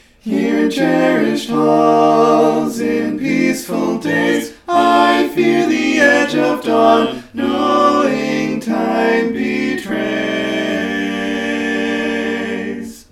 Key written in: G Minor
How many parts: 4
Type: Barbershop
All Parts mix:
Learning tracks sung by